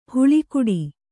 ♪ huḷi kuḍi